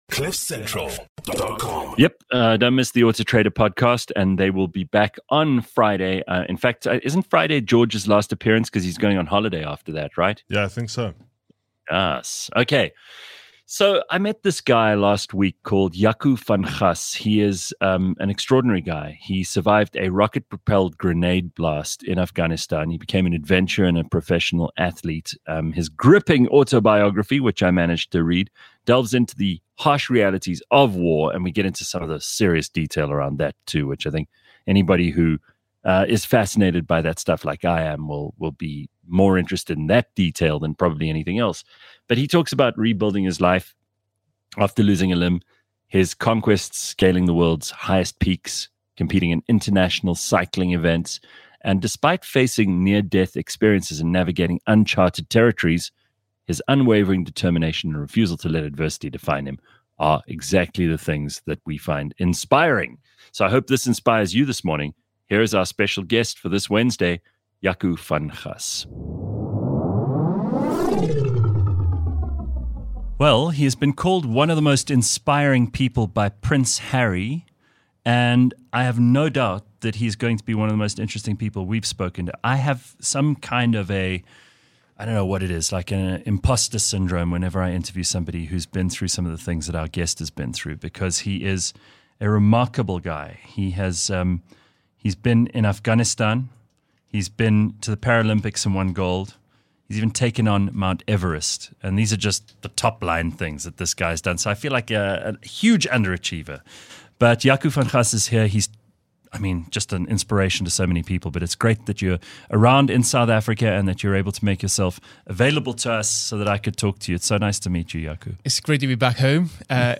A live podcast show, that’s like a morning radio show, just much better. Live from 6h00-8h00 Monday, Wednesday and Friday. Clever, funny, outrageous and sometimes very silly.